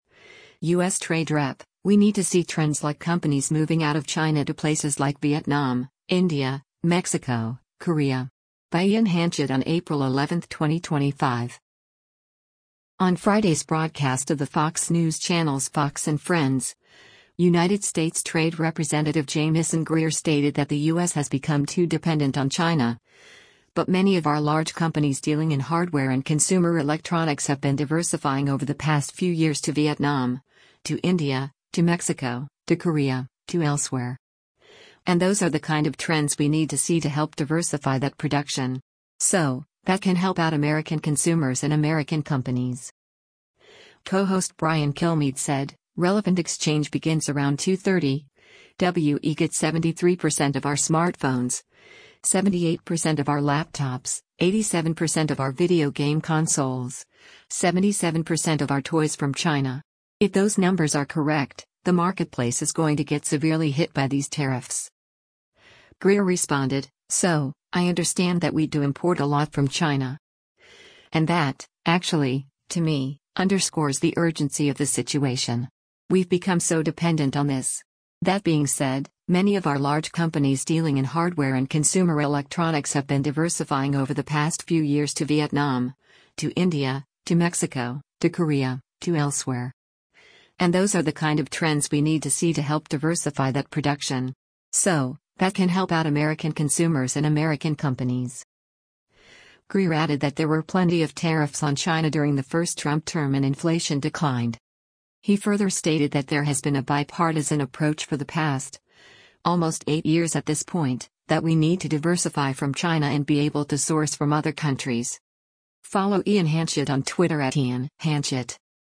On Friday’s broadcast of the Fox News Channel’s “Fox & Friends,” United States Trade Representative Jamieson Greer stated that the U.S. has become too dependent on China, but “many of our large companies dealing in hardware and consumer electronics have been diversifying over the past few years to Vietnam, to India, to Mexico, to Korea, to elsewhere. And those are the kind of trends we need to see to help diversify that production. So, that can help out American consumers and American companies.”